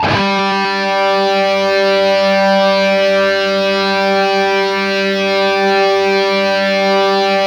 LEAD G 2 LP.wav